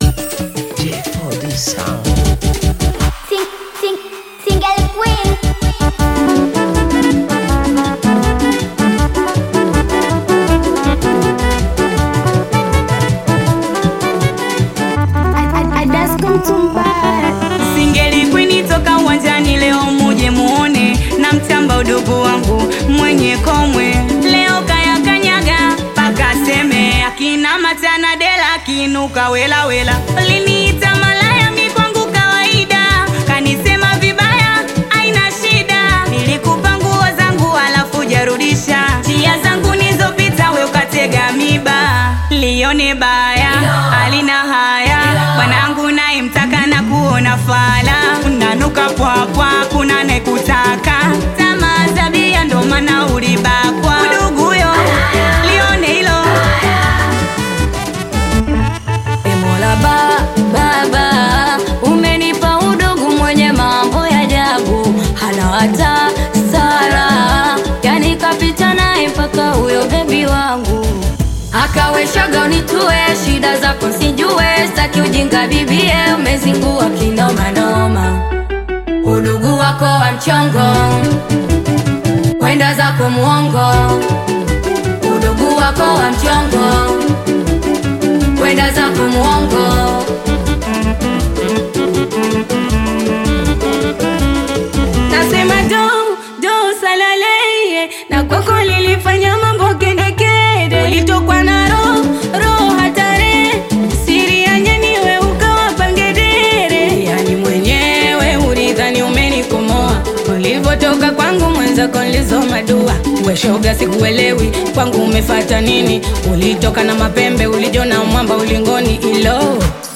Tanzanian all-female collective
Genre: Singeli